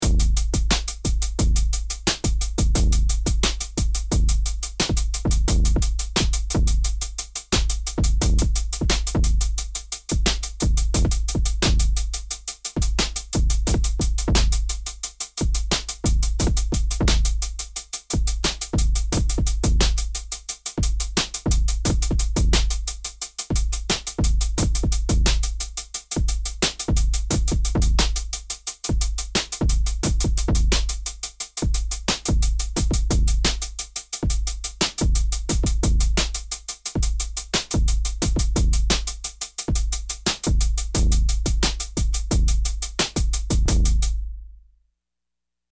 NOTE: The snare lands on the 2nd and 4th beat, and remains the same throughout this entire article.
In the examples below, the Shape function is applied to the kick drum, while leaving the hi-hats in their normal state.
Kick Shape Down
Because the kick drum pattern is more sparse and syncopated than the hi-hat, the rhythms that Shape produces can be more erratic and unpredictable.